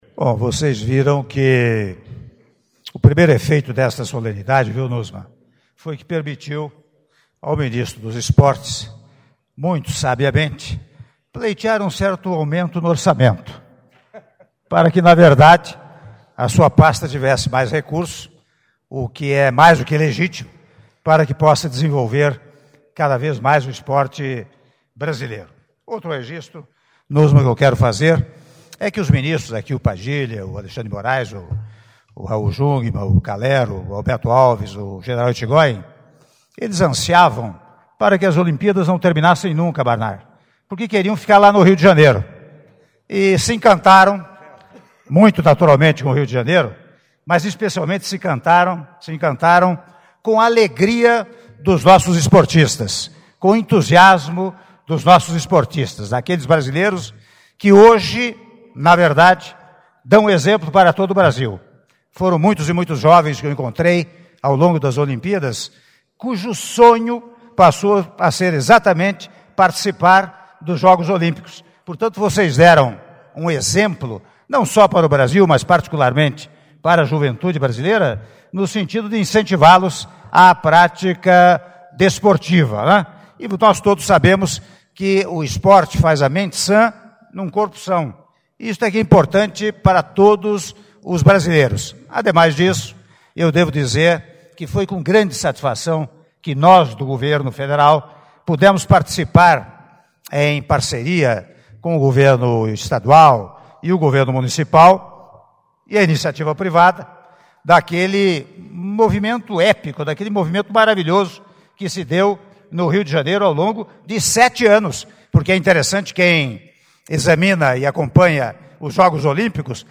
Áudio do discurso do Senhor Presidente da República em exercício, Michel Temer, durante Recepção aos atletas olímpicos - Brasília/DF (04min28s)